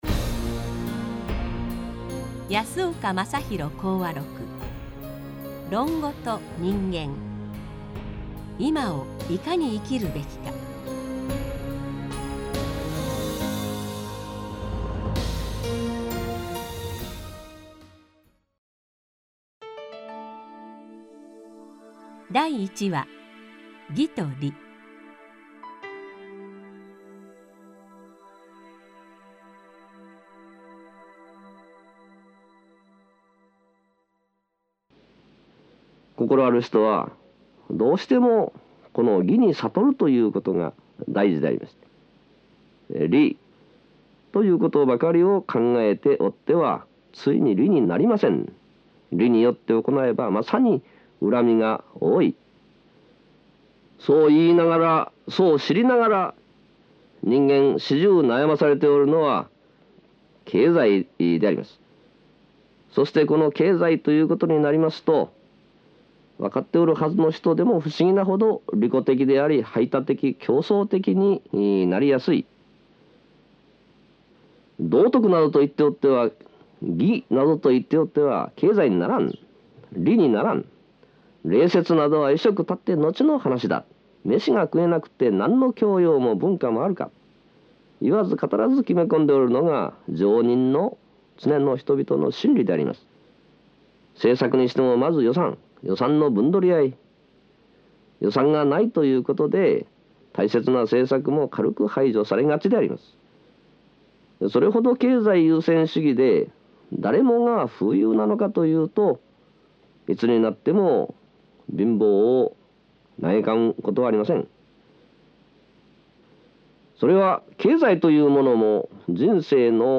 CDに収録した音声のサンプルを試聴できます。
＜特長1＞安岡正篤氏が自ら語った「安岡論語」の決定版CD集。 ＜特長2＞『論語』の名言を解釈しながら、現代人の危機を説く。
また、講演当時の音声記録をベースとしているため、音声の一部に乱れがございます。